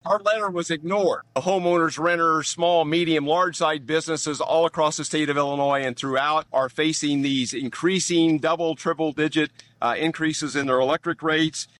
State Representative Brad Halbrook and Freedom Caucus Chairman State Representative Chris Miller published a video on Friday blasting Democrats for calling the hearing without consulting GOP House members.